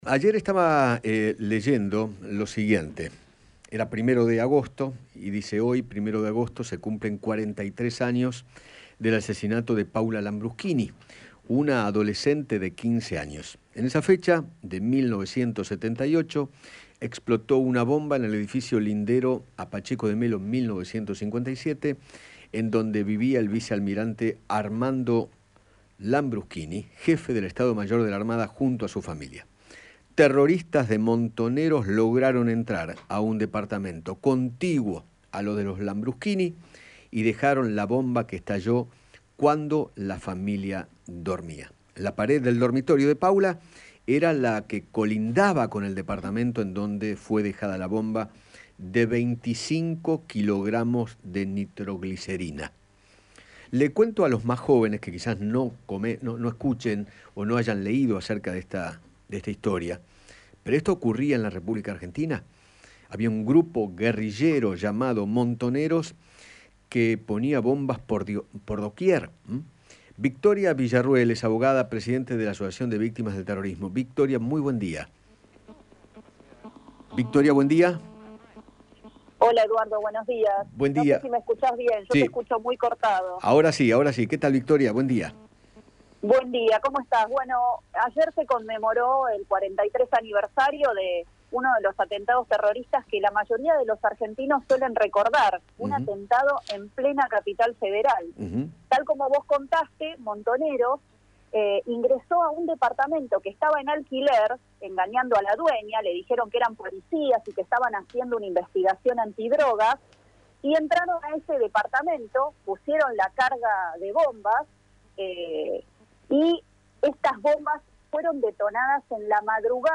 En diálogo con Radio Rivadavia, Victoria Villarruel, abogada y presidenta del Centro de Estudios Legales sobre el Terrorismo y sus Víctimas, denunció a Wado de Pedro, señalando que el ministro cobró una indemnización por sus padres fallecidos como si fueran desaparecidos de la dictadura militar cuando en realidad murieron en combate.